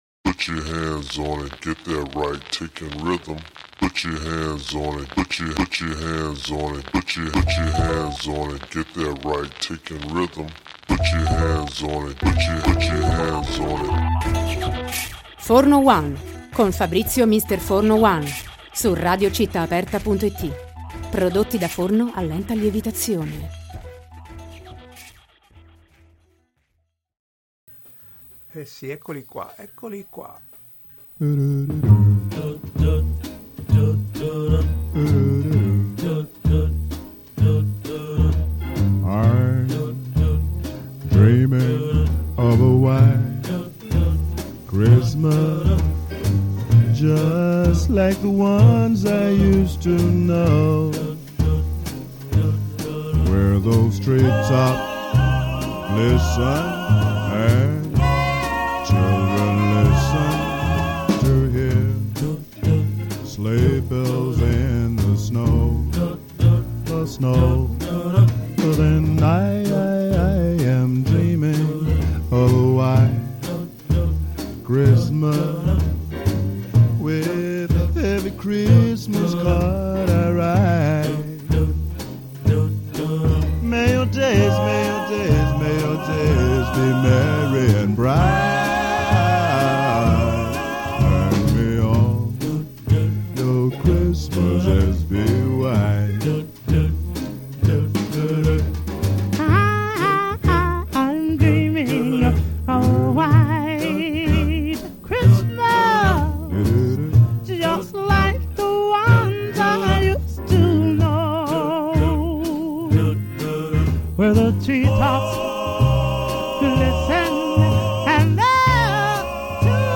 Tra le numerose versioni dei brani più celebri elencati nel libro, a corredo dell’intervista troverete due pezzi dall’inevitabile matrice black, consona allo spirito della trasmissione.